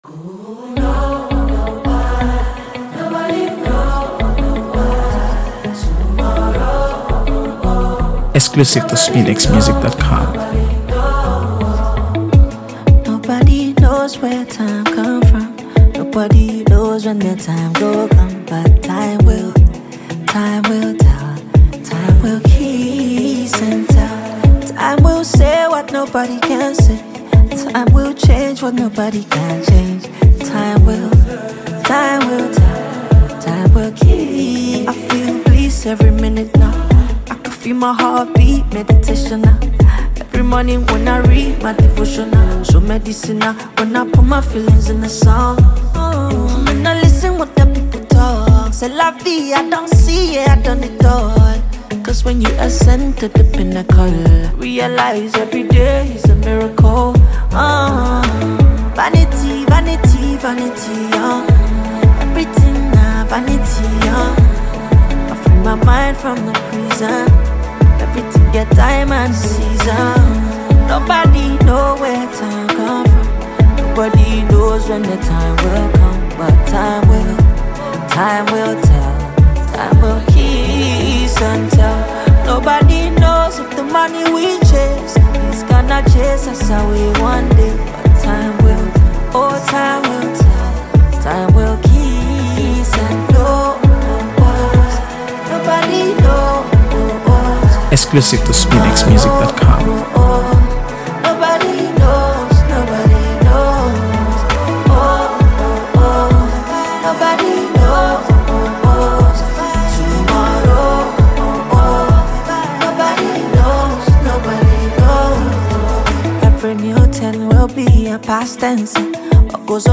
AfroBeats | AfroBeats songs
soulful melodies